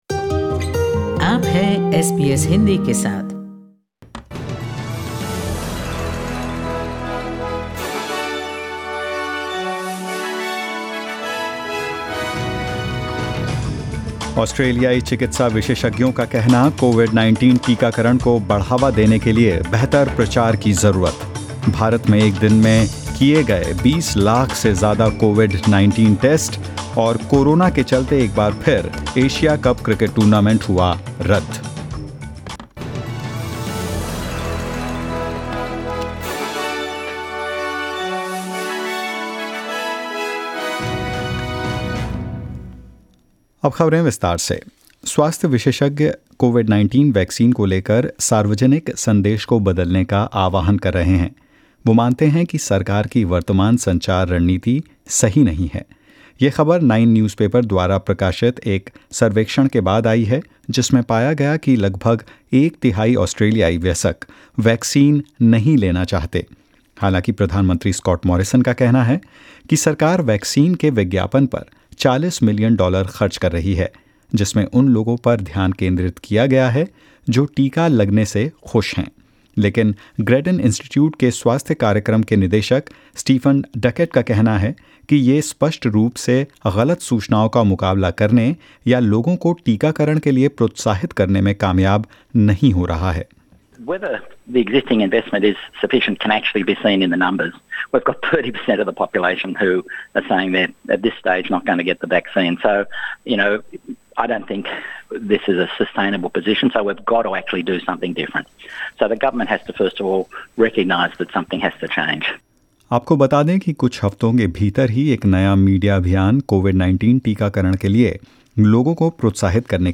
In this latest SBS Hindi News bulletin of India and Australia : NSW to open 25 new Pfizer COVID-19 vaccination sites; Asia Cup 2021 cancelled over COVID-19 threat, confirms Sri Lanka Cricket official, and more. 20/5/21